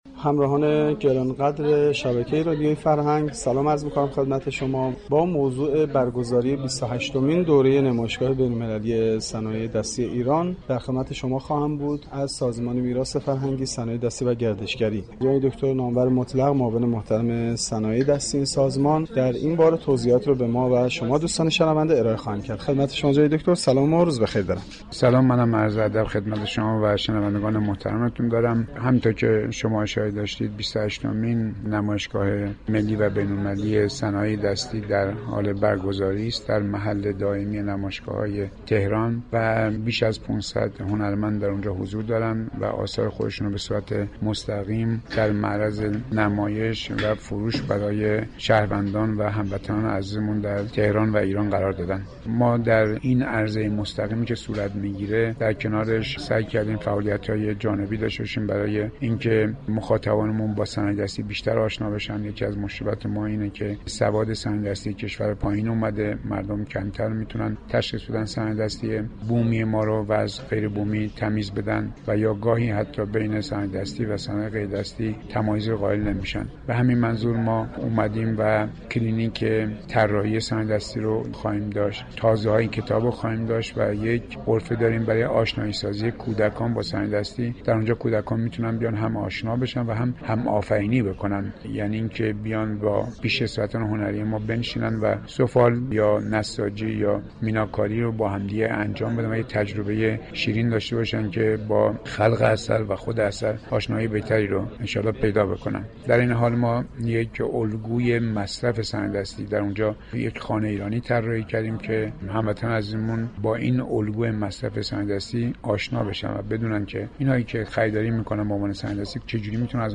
گفتگوی اختصاصی